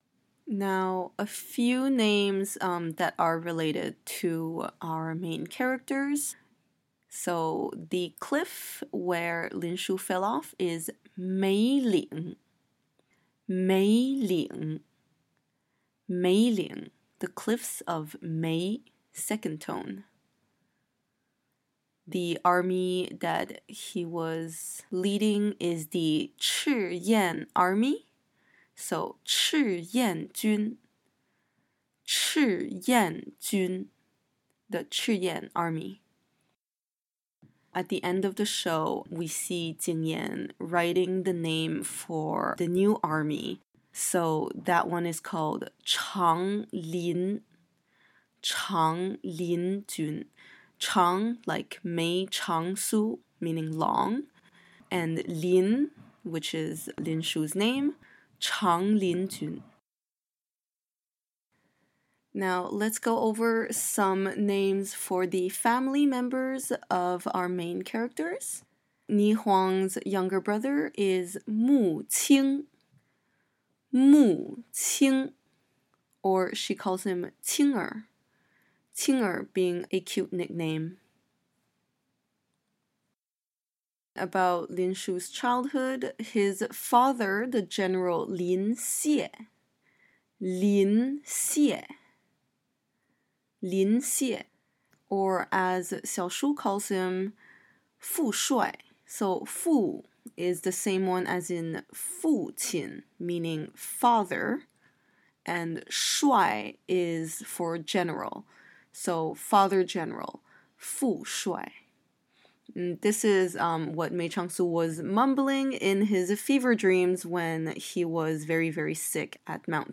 Nirvana in Fire pronunciation guide
I believe my accent is fairly Standard but feel free to disagree.